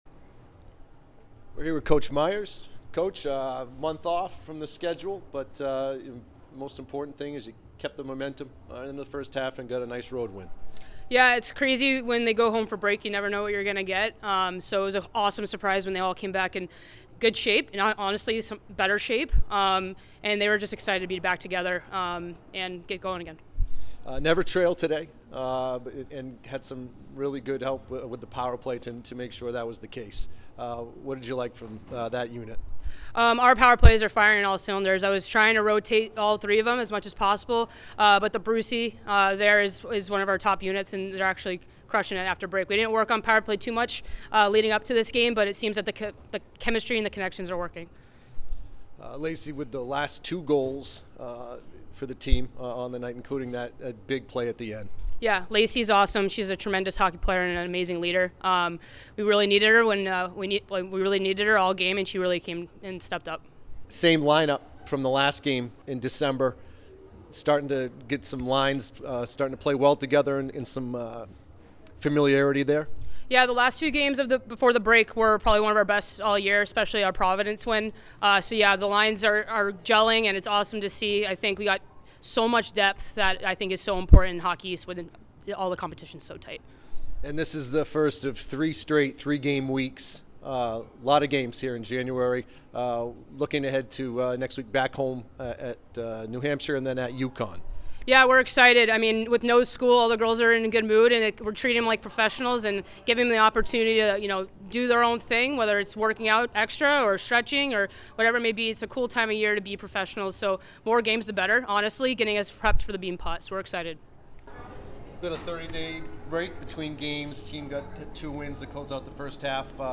Women's Ice Hockey / Maine Postgame Interview (1-8-24) - Boston University Athletics